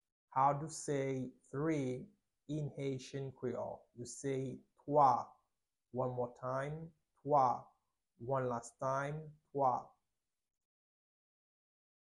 Listen to and watch “Twa” audio pronunciation in Haitian Creole by a native Haitian  in the video below:
6.How-to-say-three-in-haitian-creole-–-Twa-pronunciation-1-1.mp3